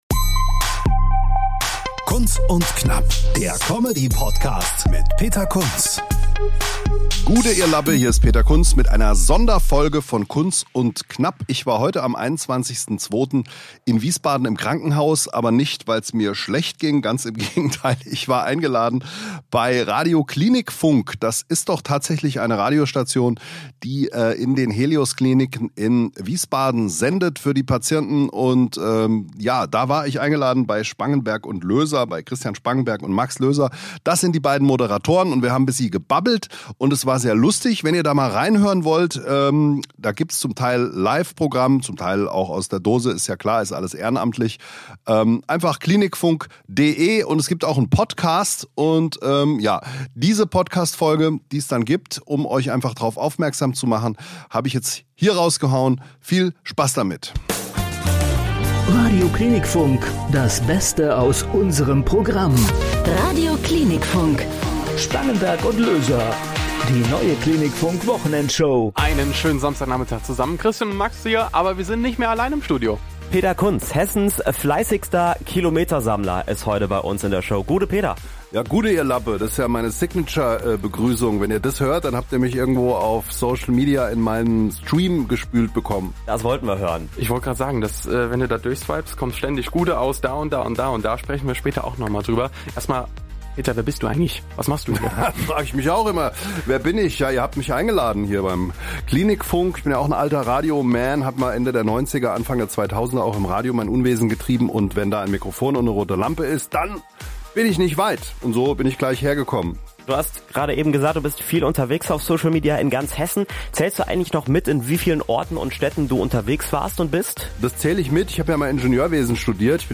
Zwischen Studiomikro, Krankenhausflur und ehrlichen Gesprächen ging’s um Comedy, Bühne, Buch – und warum gute Laune manchmal die beste Medizin ist.